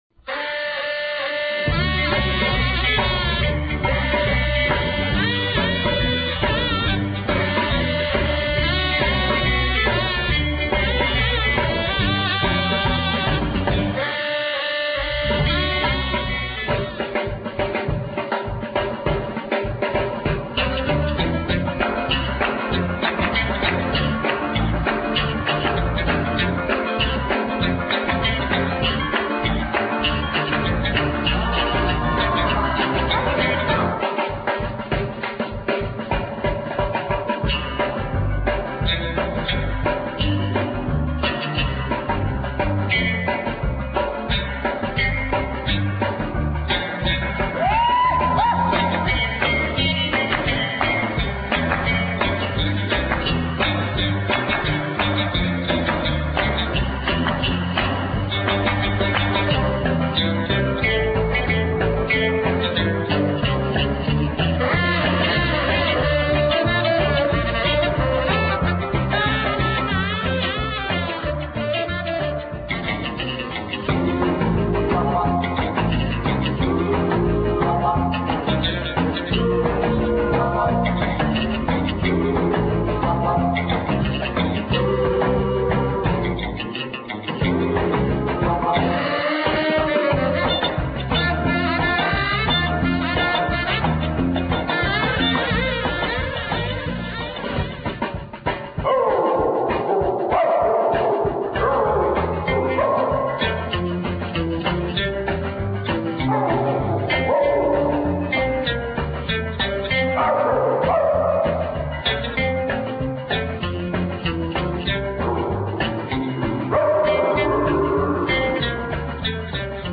Put on your saddle shoes and let's rock!
Instrumental.